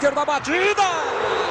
narracao-milton-leite-olha-a-batiiida-sportv-1.mp3